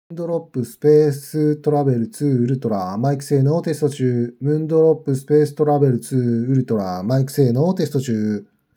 マイク性能は平均的
✅マイクテスト